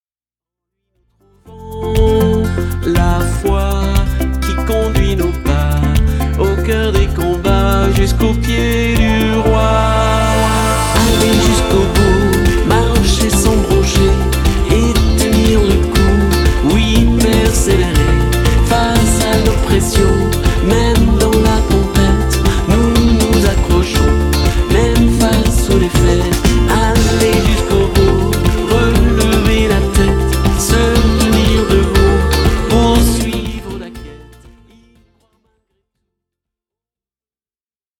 Une pop-Louange actuelle à la fois profonde et dansante